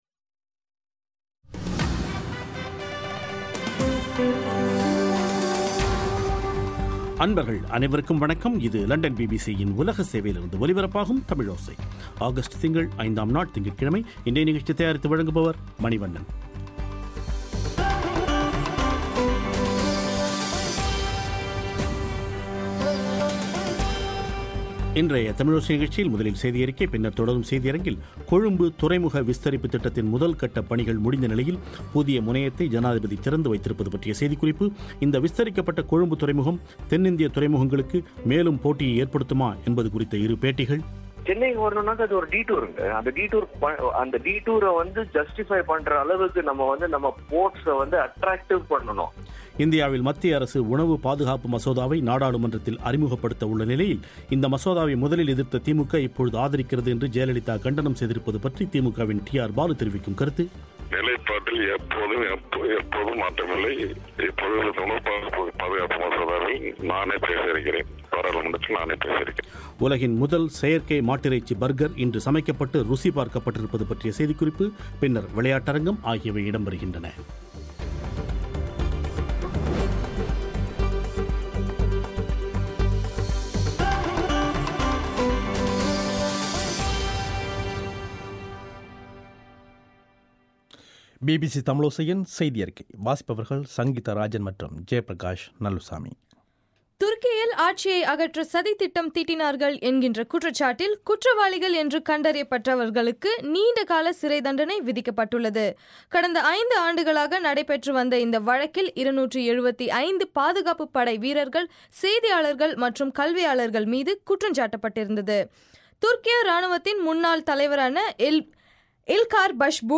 இன்றைய தமிழோசை நிகழ்ச்சியில் முதலில் செய்தி அறிக்கை பின்னர் தொடரும் செய்தி அரங்கில்,